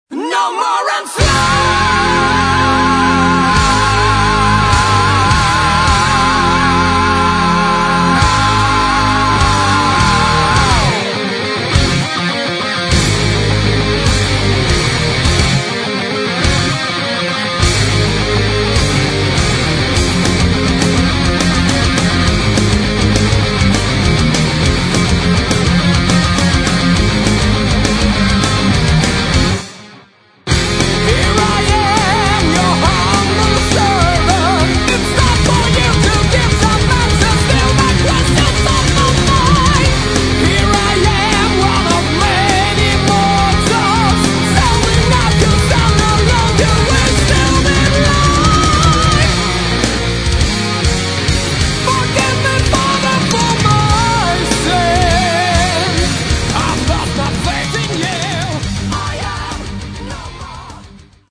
Metal
гитара
барабаны
вокал
бас